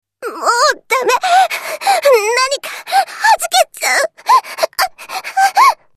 ＣＶ：草柳順子
サンプルボイス：　【１】　【２】